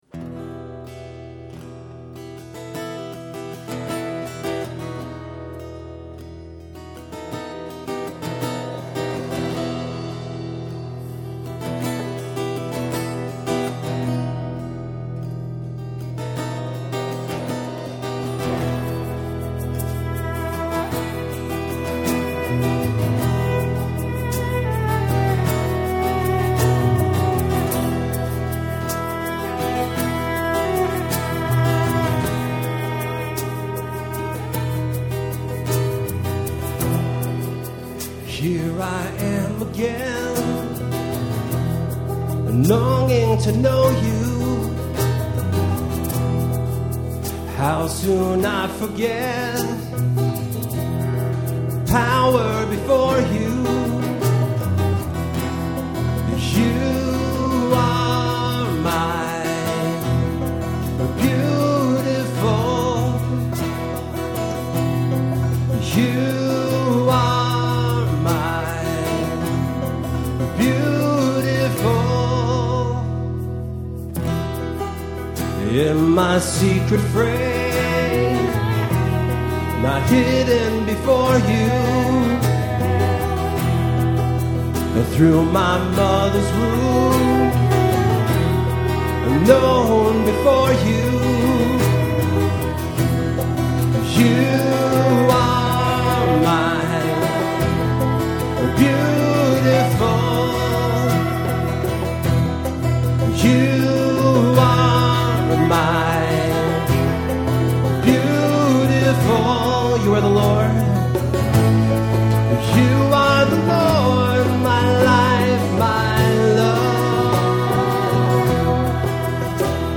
Performed live at Terra Nova - Troy on 7/27/08